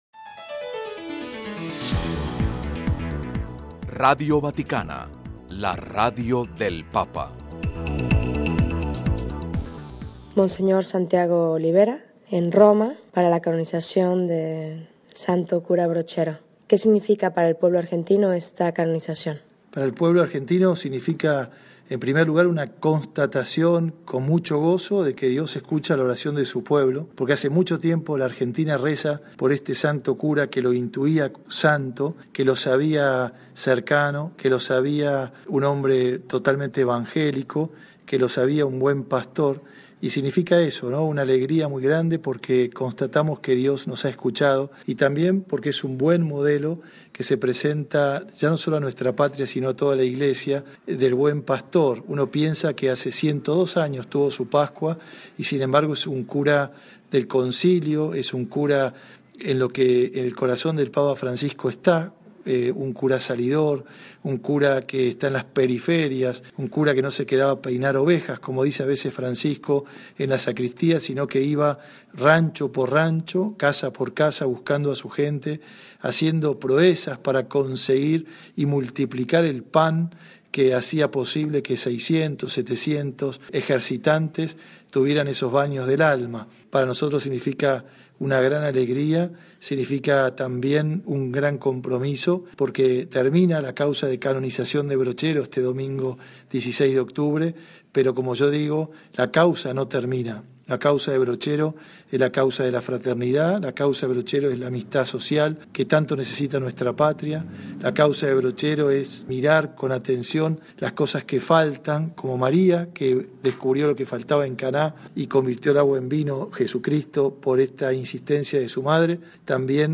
(RV).- Con ocasión de la Canonización del Beato Cura Brochero el próximo domingo 16 de octubre en Radio Vaticano entrevistamos a Monseñor Santiago Olivera, Obispo de Cruz del Eje quien relata cómo vive el pueblo argentino la Canonización del primer sacerdote santo argentino a quien desde hace mucho tiempo la Argentina le tiene devoción.